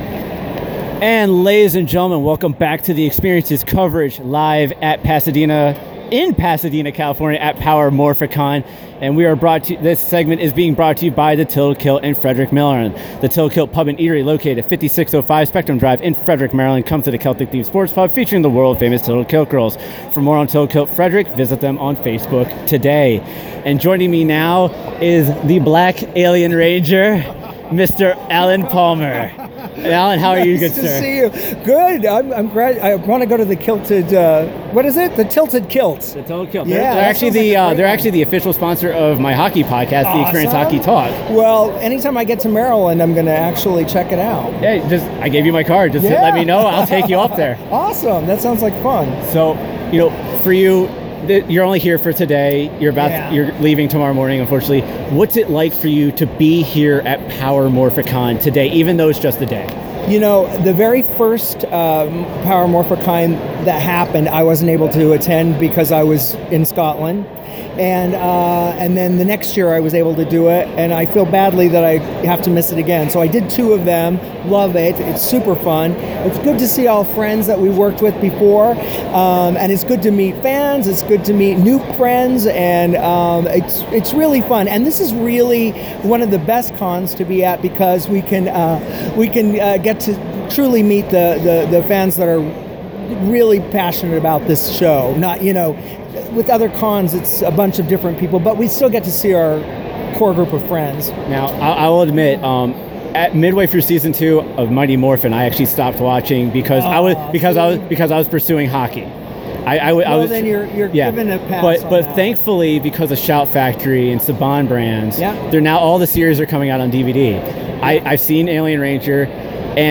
All 15 interviews were recorded live, on the floor of Power Morphicon 2016 in Pasadena, CA, and features adverts of promotions that X75 Productions was working with at that time.
Day 1 Interviews